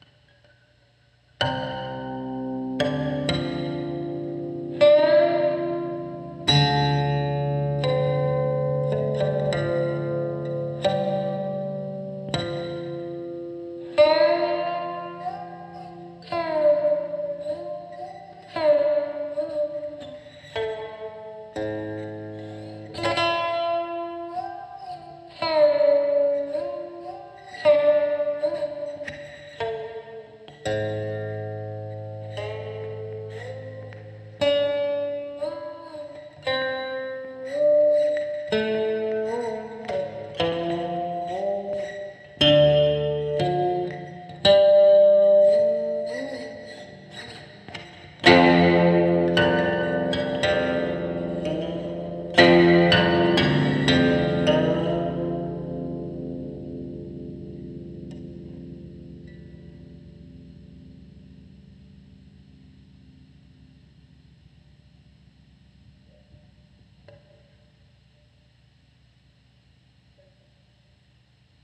Electric Guqin
Guqin is known for its quiet volume. To enhance the instrument, allowing it to accompany other instruments, I implemented a dual pickup system: a piezoelectric pickup on the right captures the clear, direct tone of plucked strings, while a coil pickup on the left end isolates its iconic overtones.
The signal was processed through an equalizer, an subtle octave effects unit, a compressor, chorus, and reverb before finally being output through a Fender amplifier.